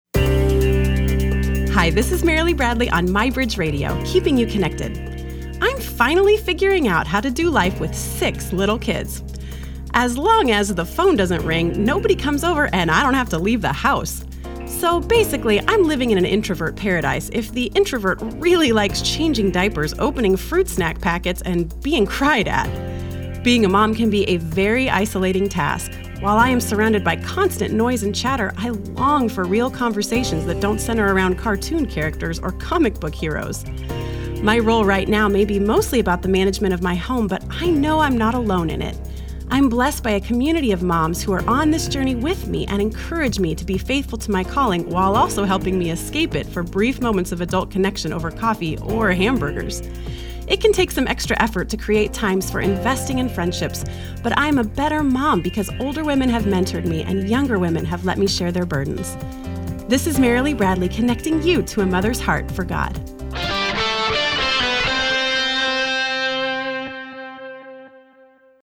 My radio spot for the week.